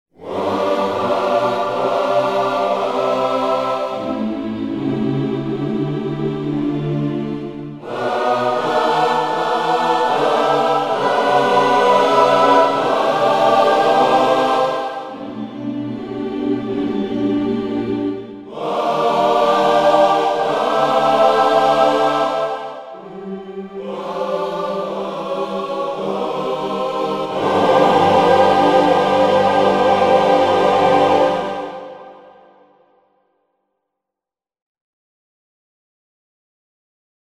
Gospel v_s 2 demo =1-B01.mp3